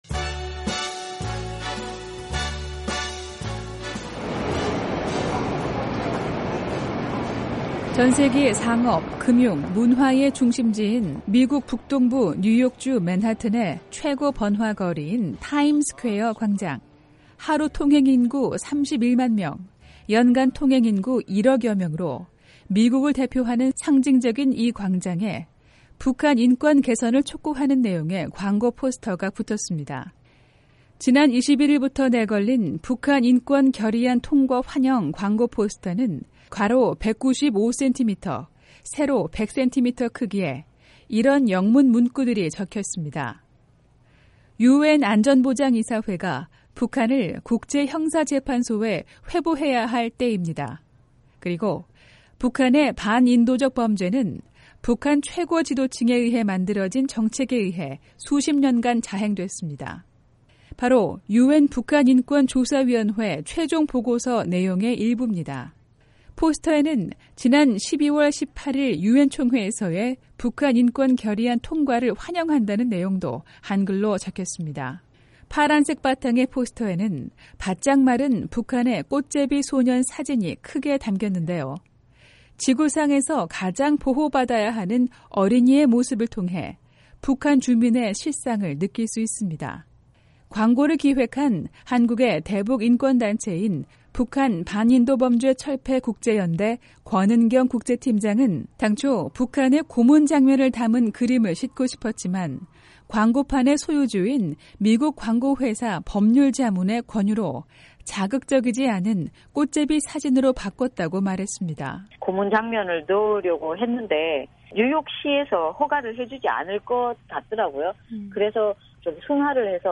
[뉴스 풍경 오디오 듣기] 뉴욕 타임스퀘어 광장에 북한인권 개선 촉구 광고